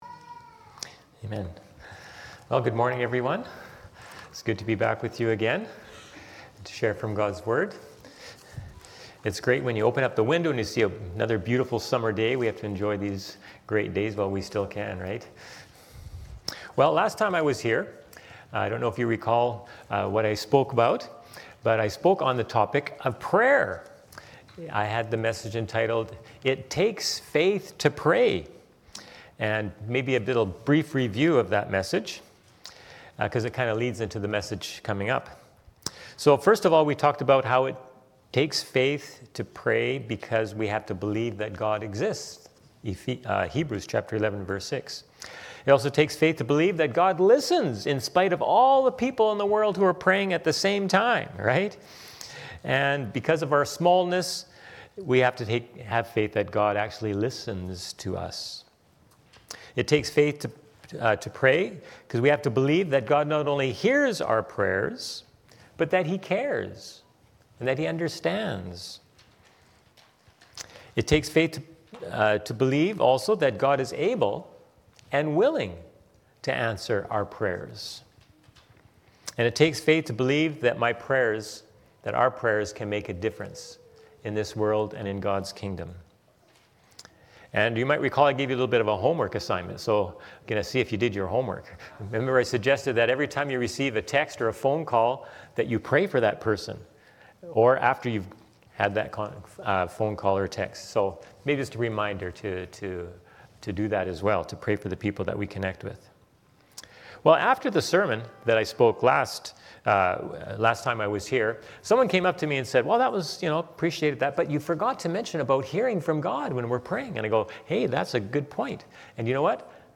Sermons | Sunrise Community Church